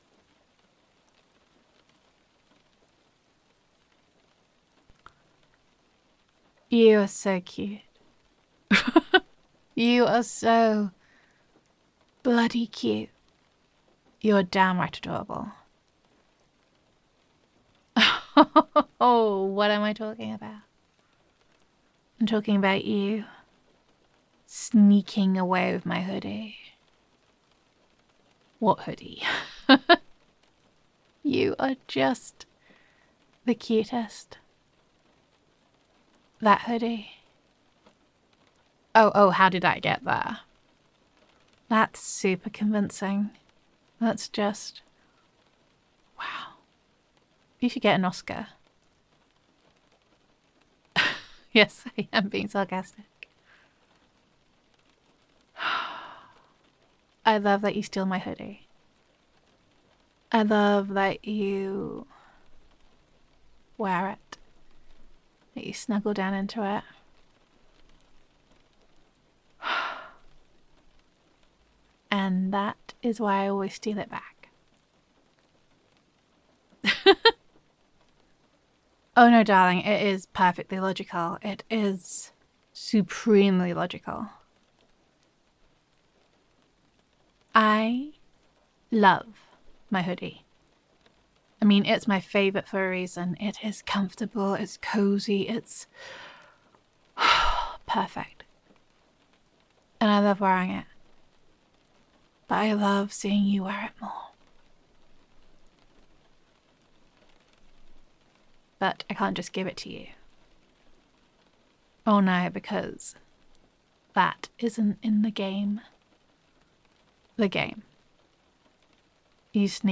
[F4A] Catching You Red Hoodie Handed [Adorable][Playful][Girlfriend Roleplay][You Are So Tricky][Adoration][Handling of Stolen Hoods][Gender Neutral][Your Girlfriend Catches You Red Hoodie Handed]